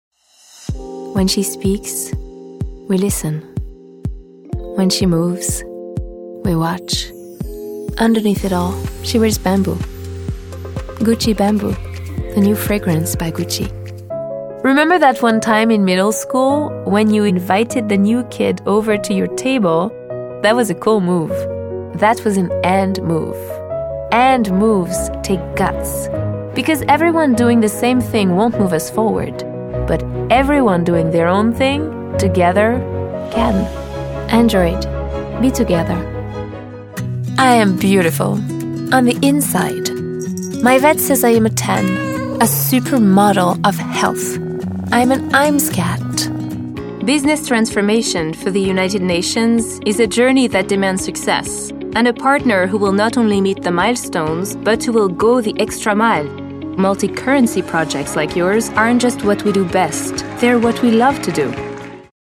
English Demo (subtle & cosmopolitan)